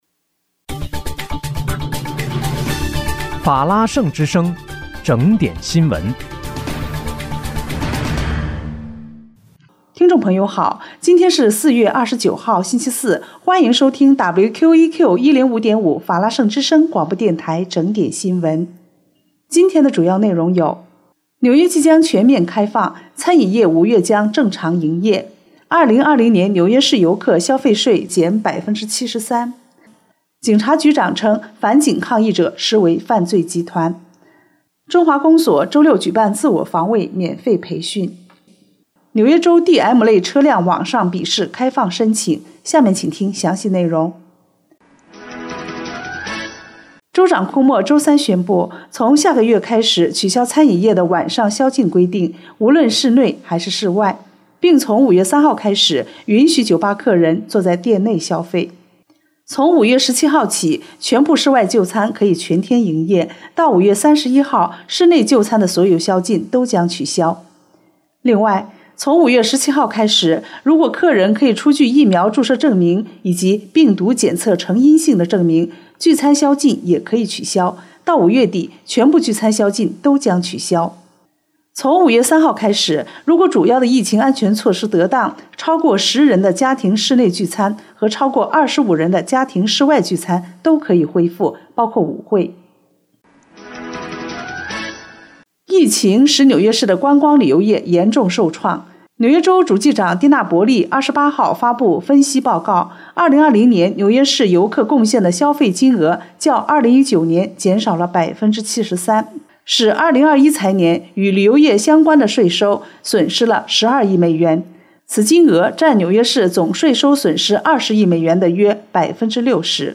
4月29日（星期四）纽约整点新闻